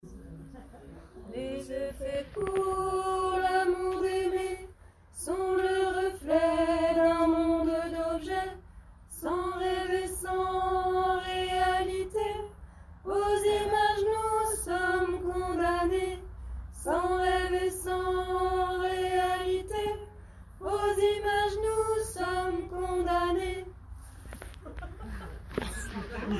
Voix haute :